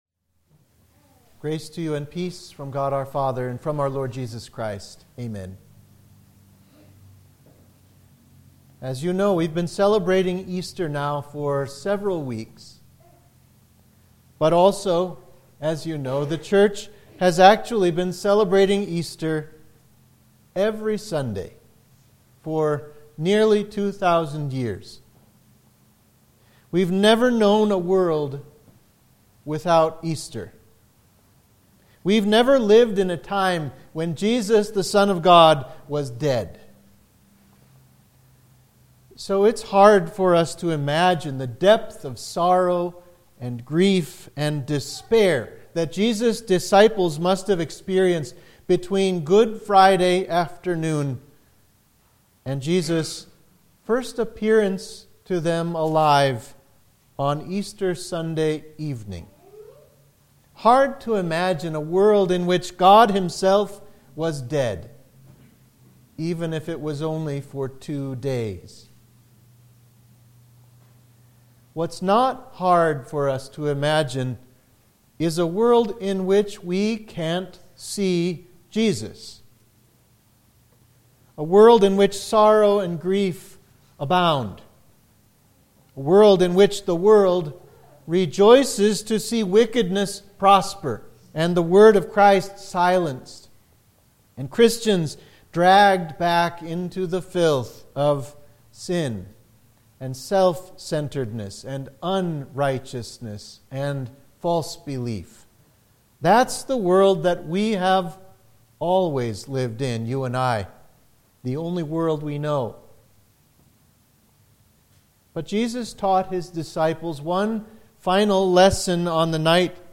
Sermon for Jubilate – Easter 3